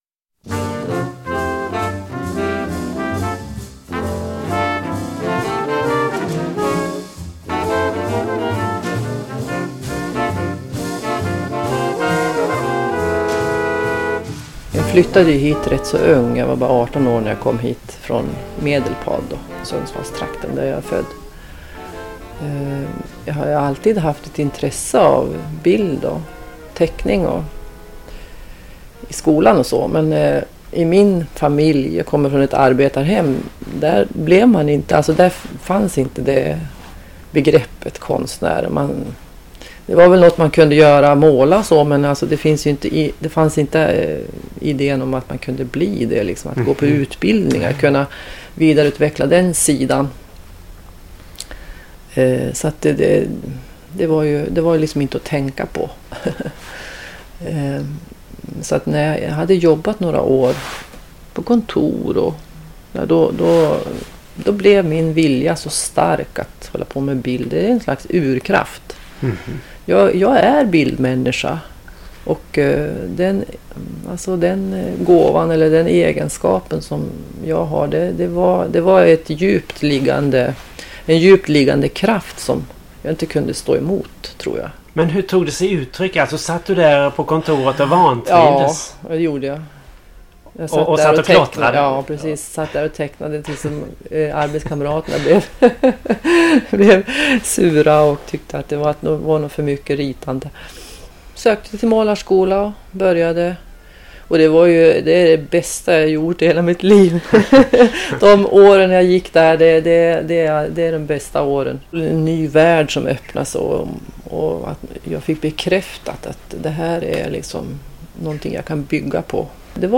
Intervju från cirka 2004.